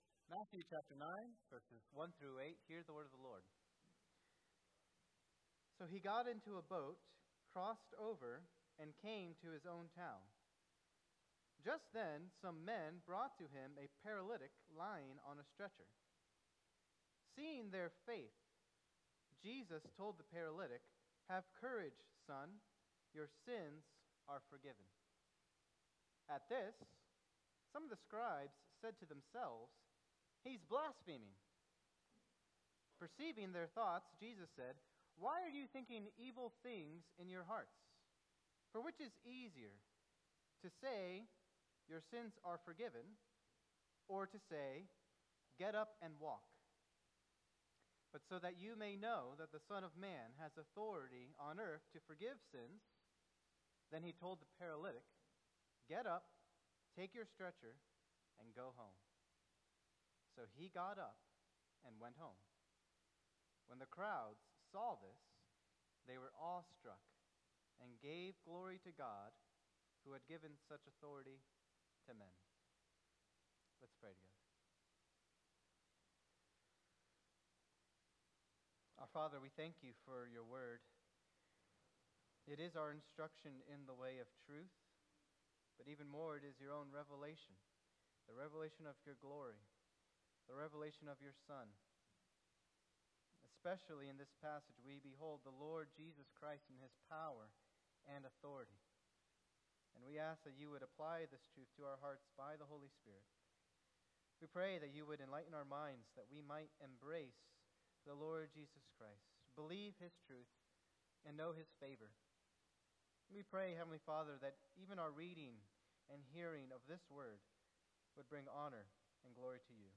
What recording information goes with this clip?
The Authority to Forgive Sins | First Baptist Church